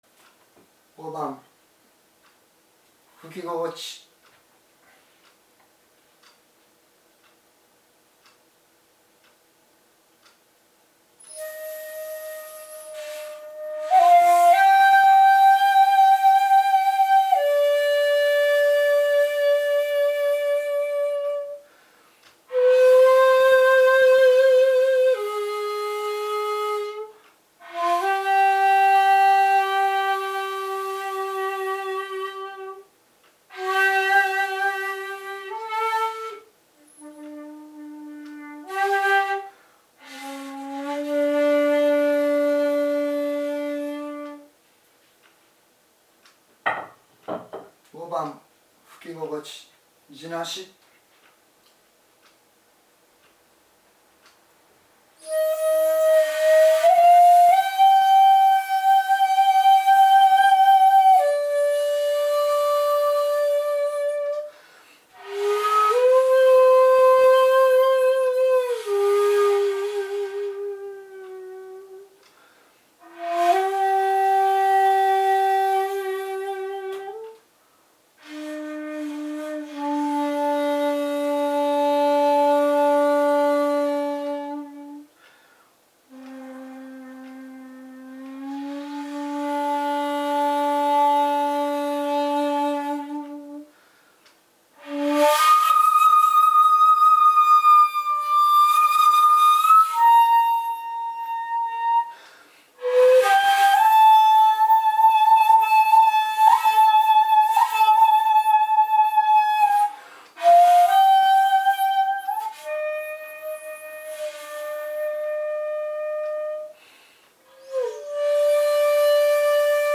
今回は如何に地無し管で琴古流本曲が生き返ってくるか、を地塗り管と実際に吹き比べてみたいと思います。
５．地無し管はいつまで吹いていても不思議と「飽き」がこない。